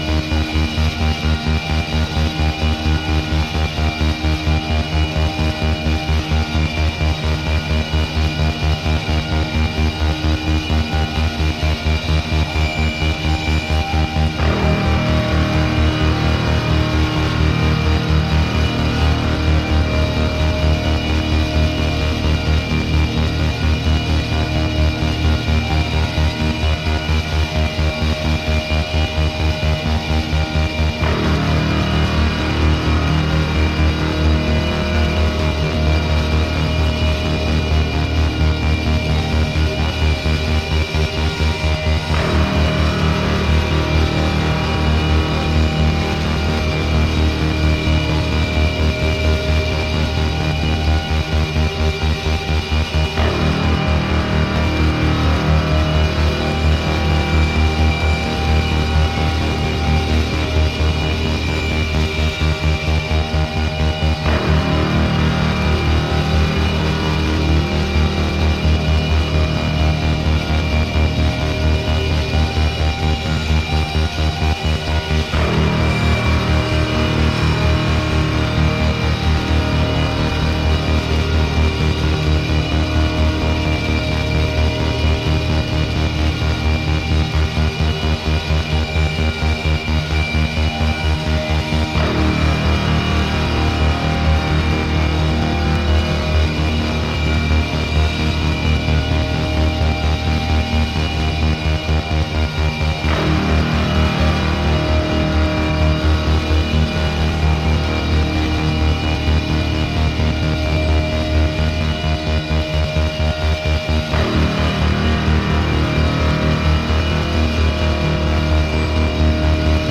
Electronix